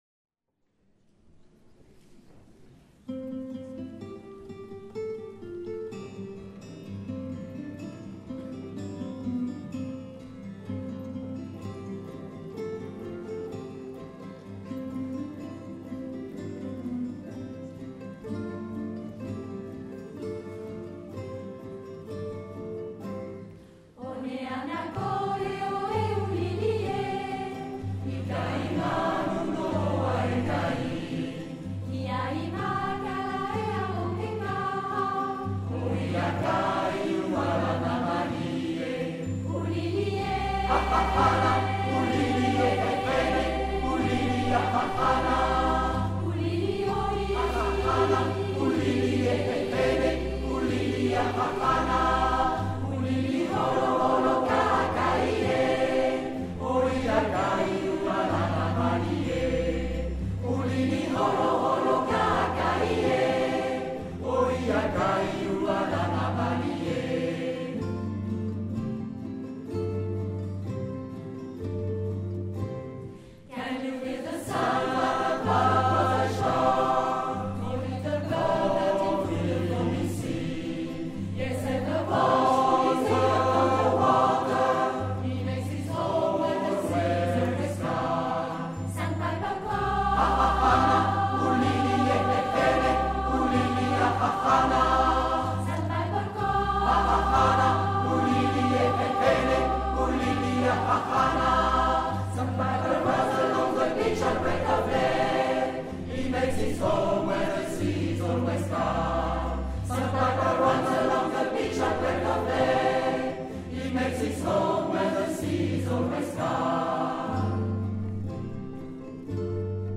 - Enregistrements de concerts 2011/2012 au format MP3 (fichier Croqnotes_2011-2012.zip à télécharger)